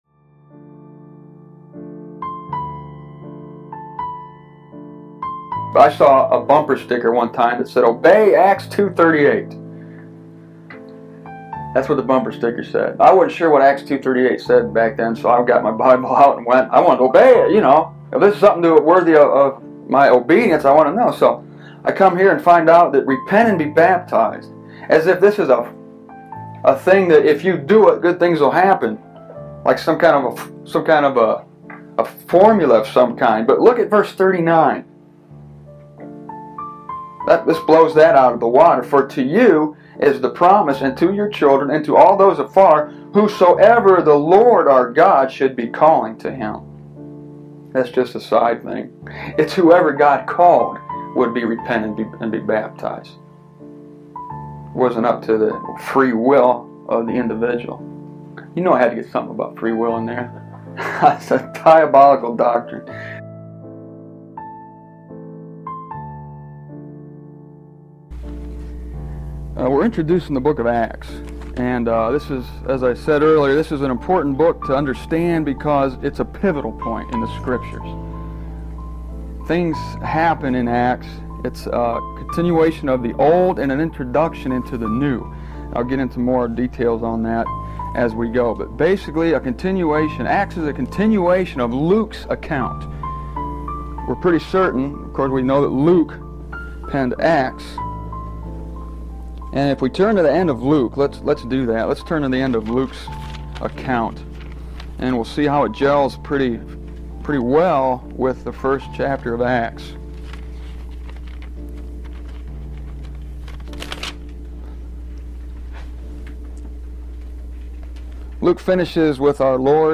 It was recorded in my living room with about a dozen people in attendance.